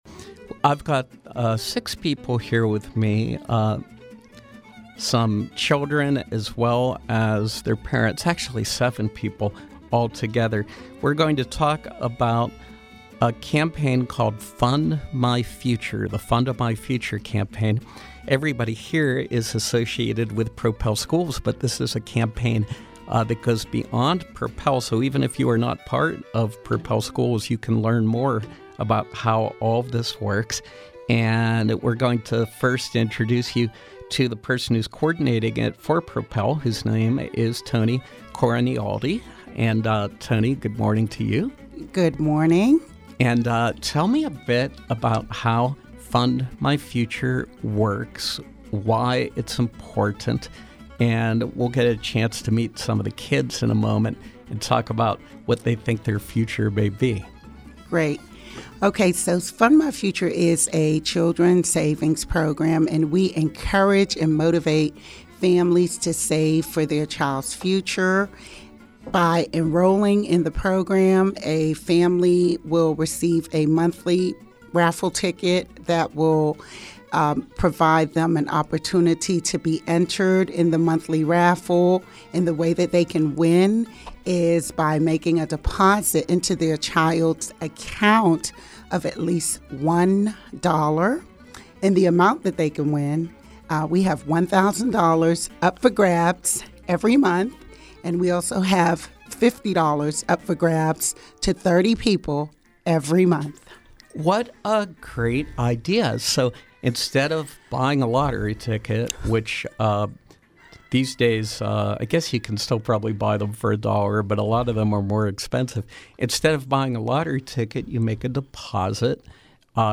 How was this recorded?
In Studio Pop-Up: Fund My Future Campaign